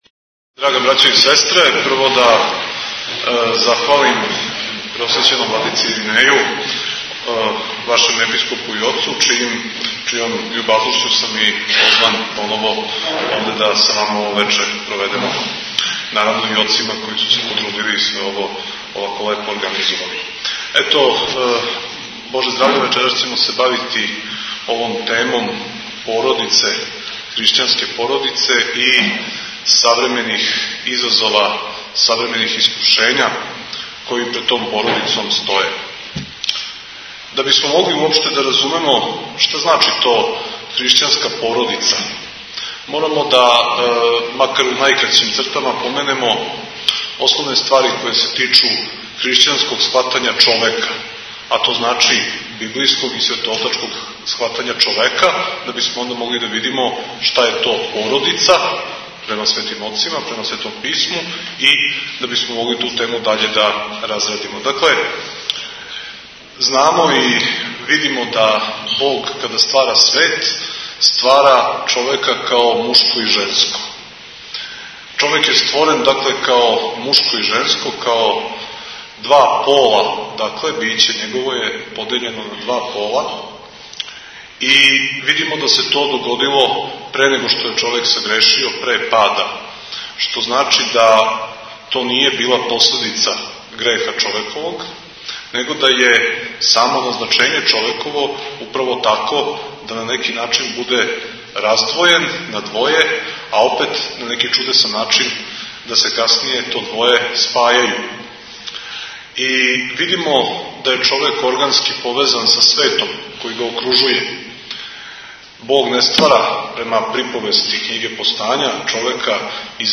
По благослову Његовог Преосвештенства Епископа бачког Господина др Иринеја, синоћ је у свечаној дворани Гимназије "Јован Јовановић Змај" у Новом Саду одржано прво предавање јесењег семестра Школе православне духовности.